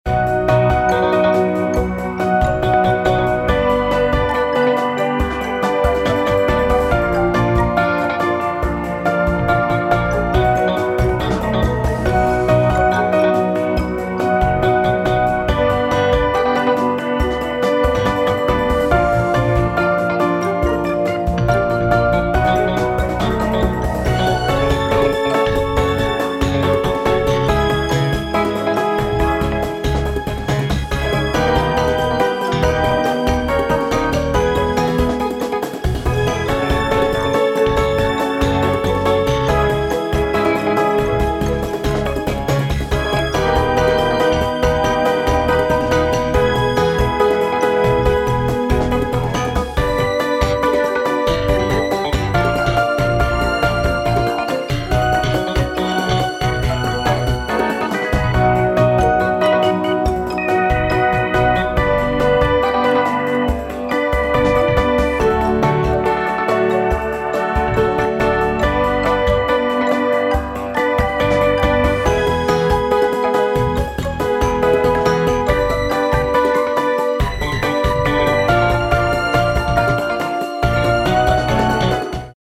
midi-demo 2